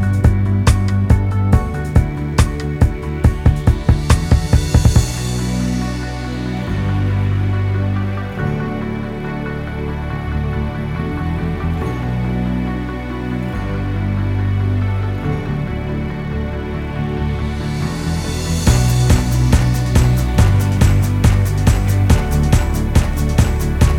no Backing Vocals Pop